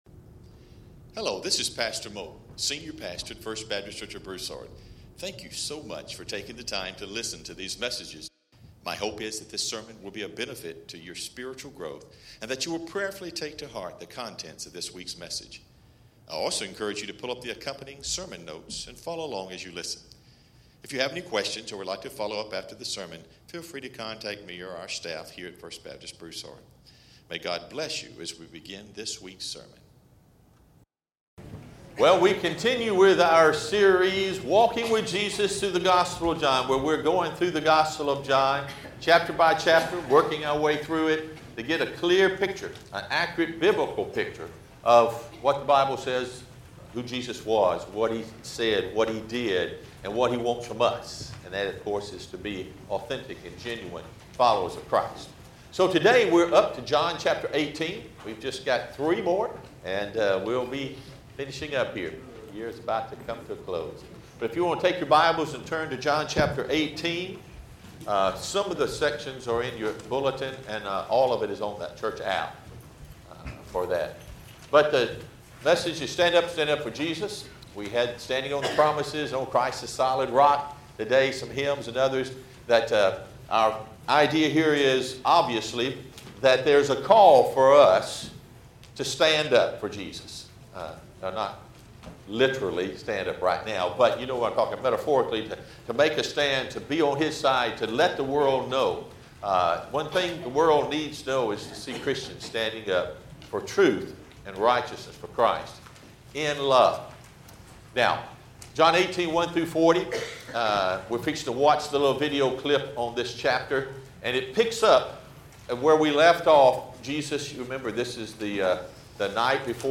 Sermon Detail | First Baptist Church Broussard
Sermon_Oct._13.mp3